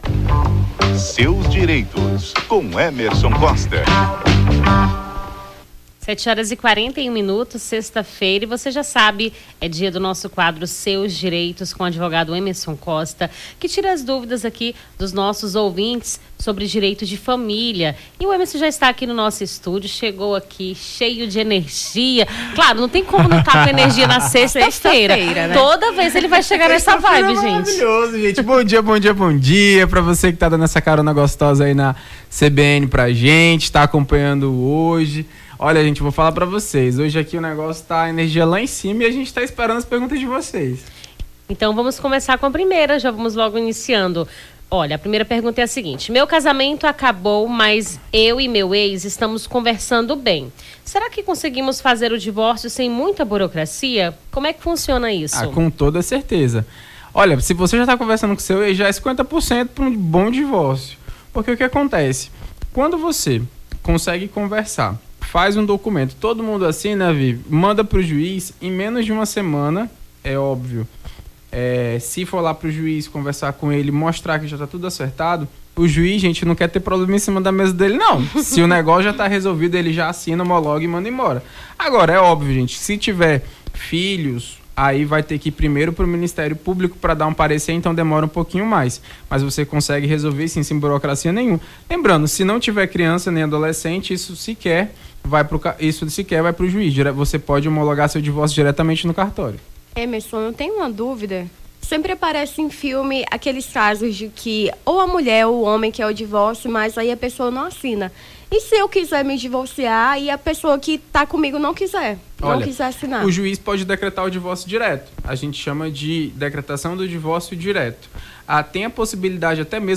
Seus Direitos: advogado tira-dúvidas sobre direito de família
Rio Branco